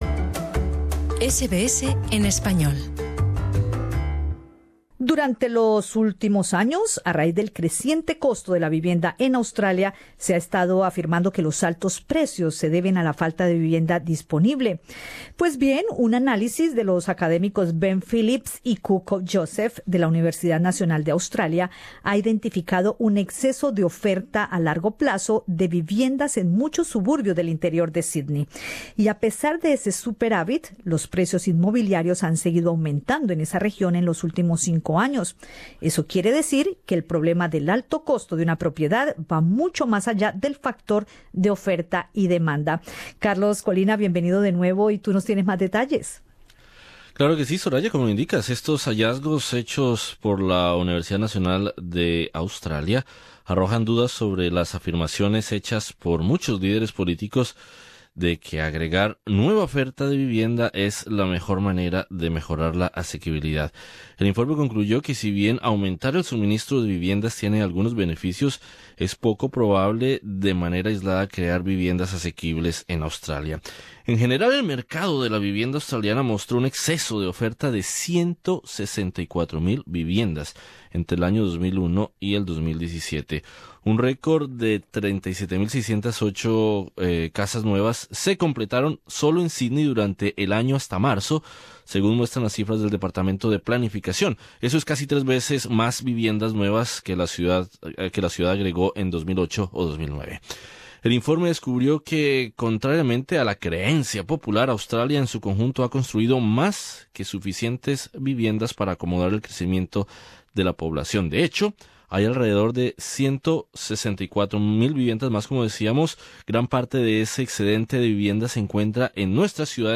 Eso quiere decir que el problema del alto costo de una propiedad va mucho más allá del factor de oferta y demanda. En entrevista con Radio SBS